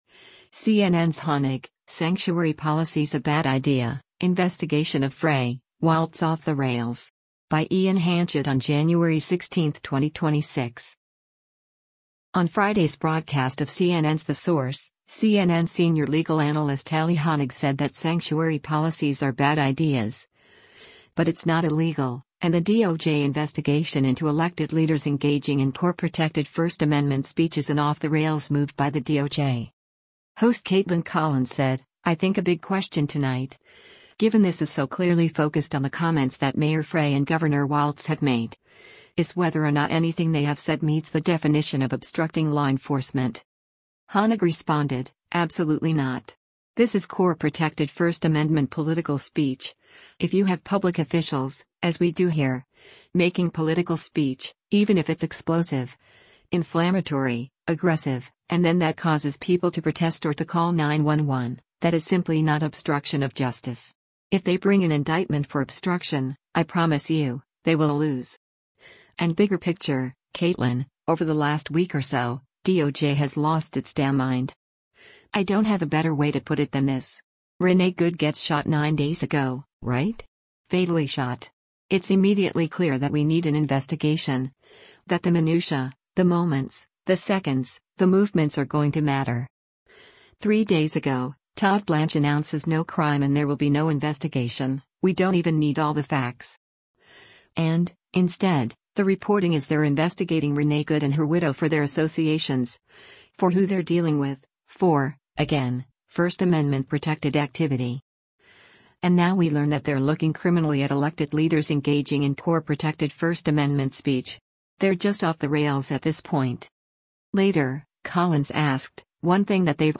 On Friday’s broadcast of CNN’s “The Source,” CNN Senior Legal Analyst Elie Honig said that sanctuary policies are bad ideas, but it’s not illegal, and the DOJ investigation into “elected leaders engaging in core protected First Amendment speech” is an “off the rails” move by the DOJ.